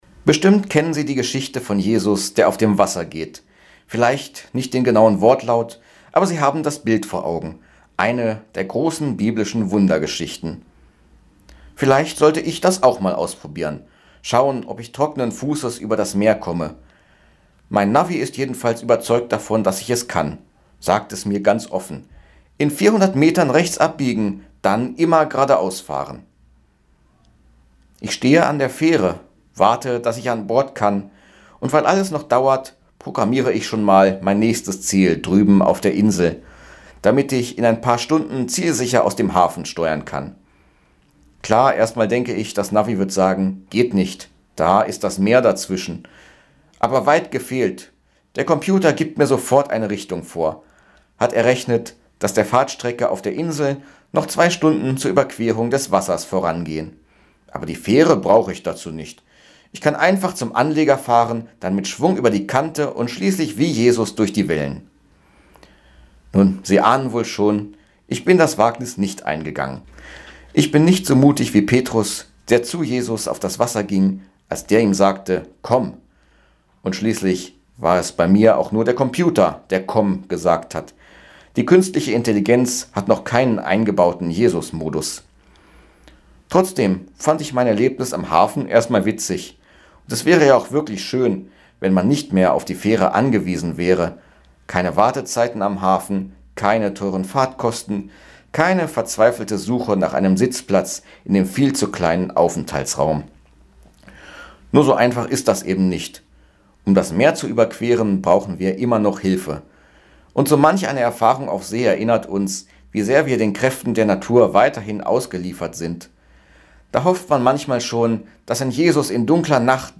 Radioandacht vom 26. Juli
radioandacht-vom-26-juli.mp3